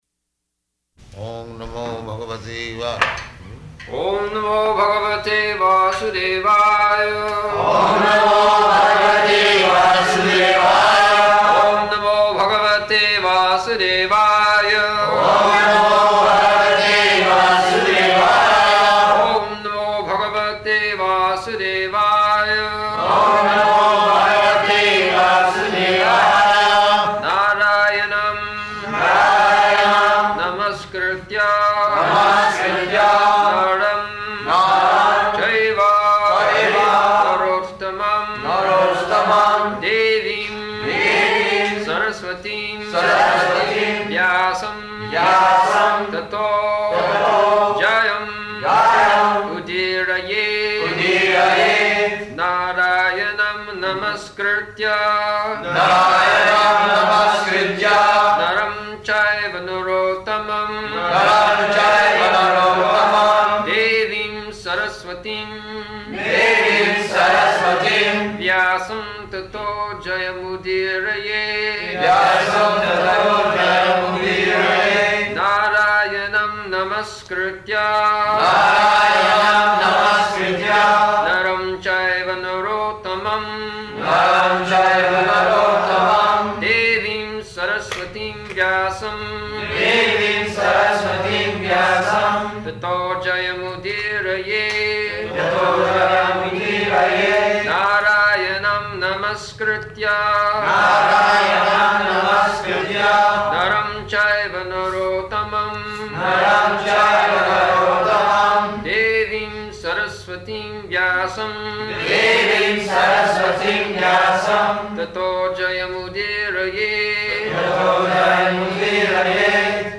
Śrīmad-Bhāgavatam 1.2.4 --:-- --:-- Type: Srimad-Bhagavatam Dated: May 28th 1974 Location: Rome Audio file: 740528SB.ROM.mp3 Prabhupāda: Oṁ namo bhagavate vā...
[leads chanting of verse, etc.]